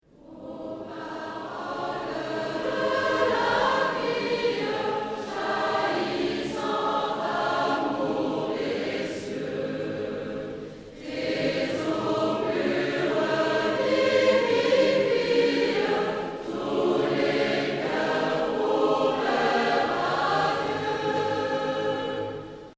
Chor